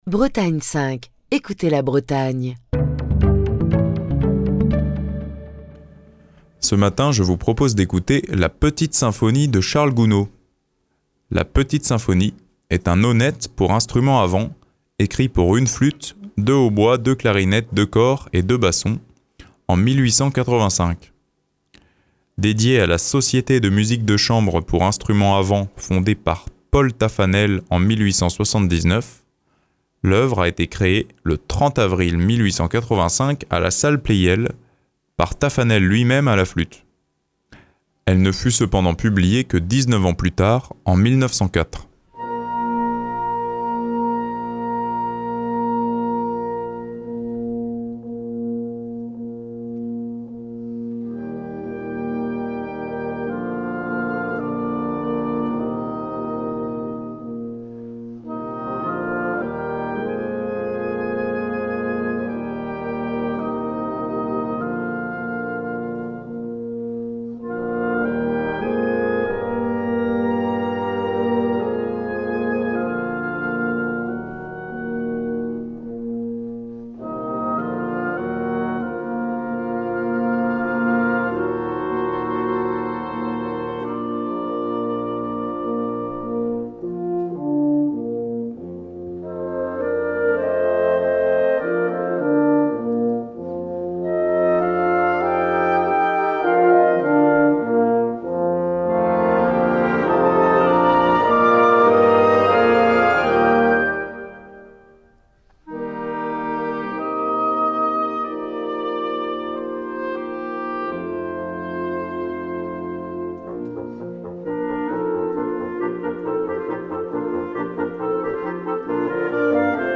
"La Petite Symphonie" de Charles Gounod est un nonette pour instruments à vent, composé en 1885. Cette œuvre est écrite pour une flûte, deux hautbois, deux clarinettes, deux cors et deux bassons.
Le Saint Paul Chamber Orchestra, un orchestre de chambre professionnel basé à Saint Paul, Minnesota, interprète cette pièce sous la direction de Christopher Hogwood.